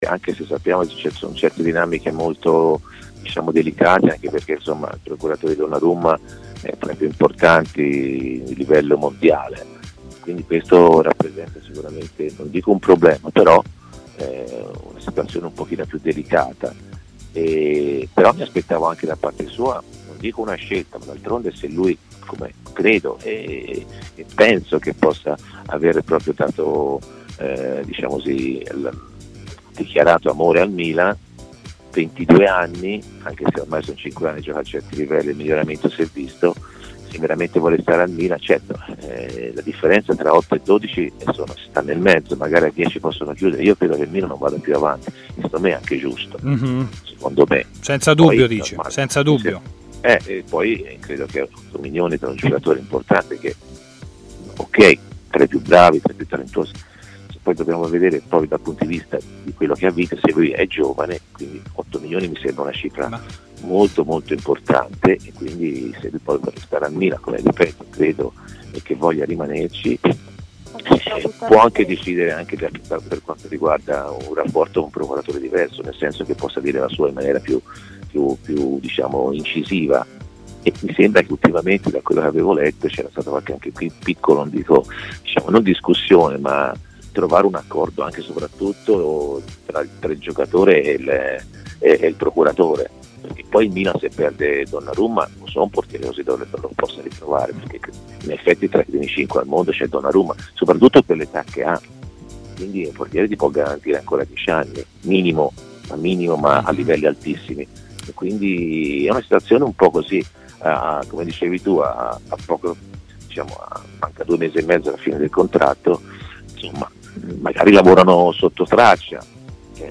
Intervistato da TMW Radio durante la trasmissione Stadio Aperto